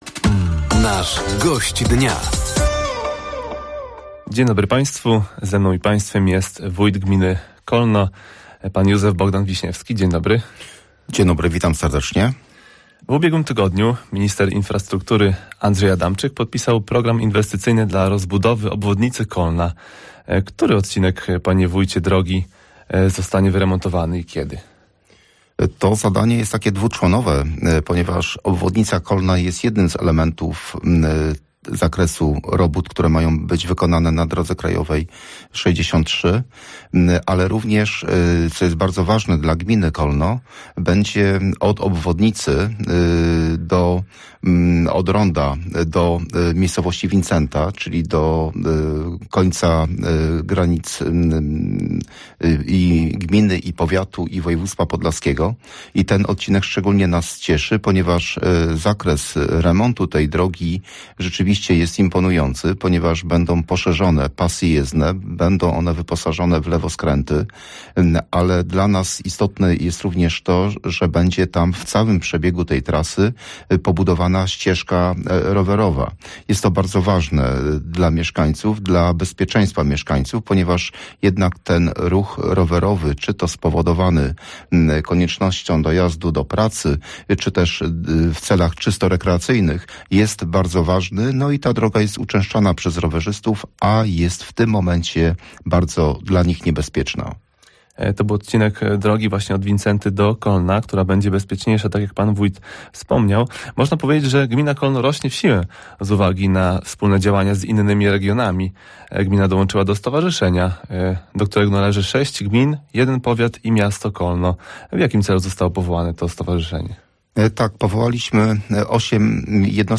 Gościem Dnia Radia Nadzieja był Wójt Gminy Kolno, Józef Bogdan Wiśniewski. Rozmowa dotyczyła inwestycji związanych z budową obwodnicy Kolna, oświetlenia drogowego czy magazynu.